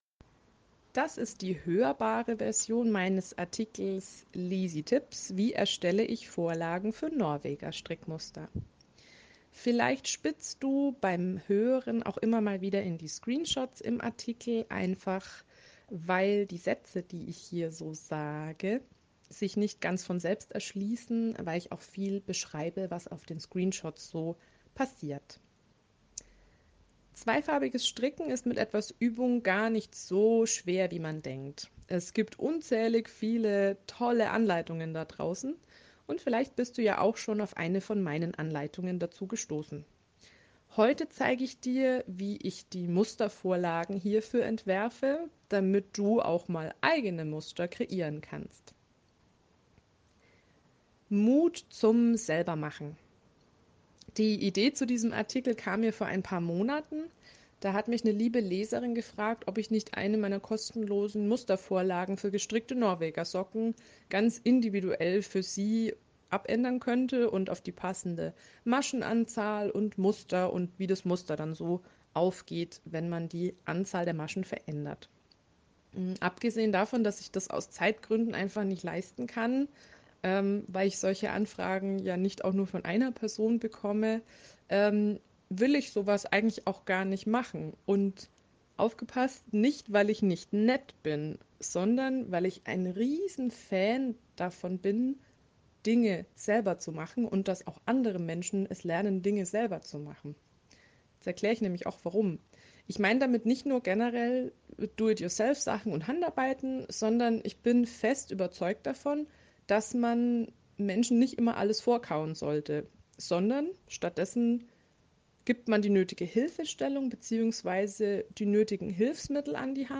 Dann höre Dir doch einfach diesen Artikel an – in der Audio-Version